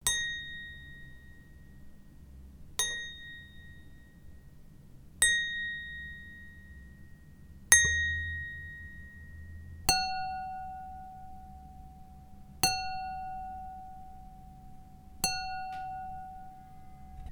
Glass and chrystal ping ding
bell cling ding glass ping sound effect free sound royalty free Sound Effects